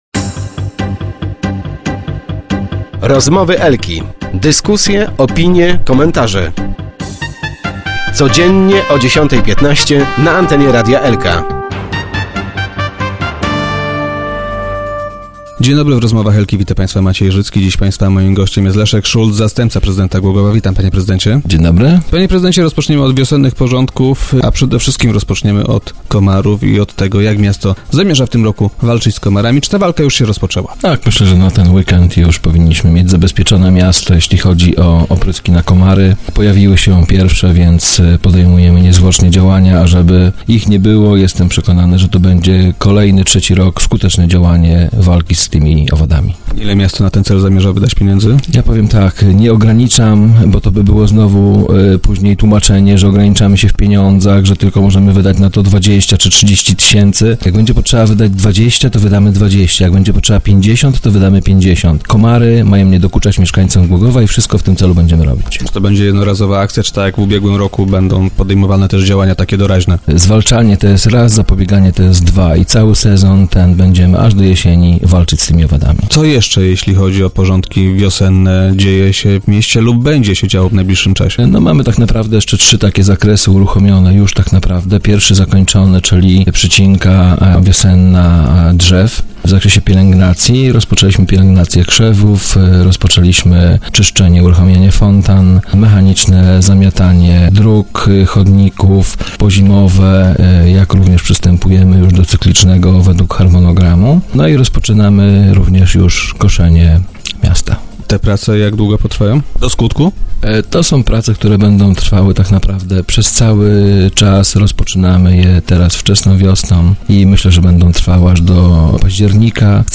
thumb_0225_szulc_w_suchawach.jpgGłogów. Władze miasta wypowiedziały wojnę komarom. Jak twierdzi Leszek Szulc, zastępca prezydenta miasta, który był gościem dzisiejszych Rozmów Elki.